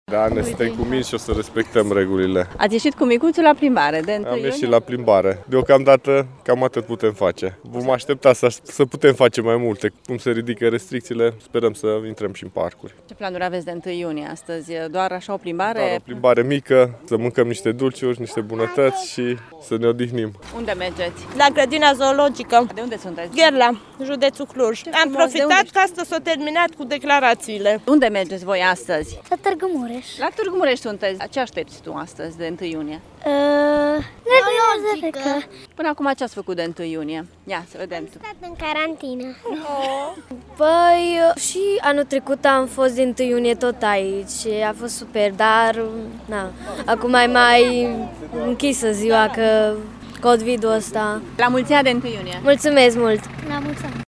Totuși, câțiva temerari s-au încumetat să iasă astăzi la o plimbare la Platoul Cornești, să viziteze Grădina Zoologică iar câteva familii cu copii au venit din județul Cluj pentru asta: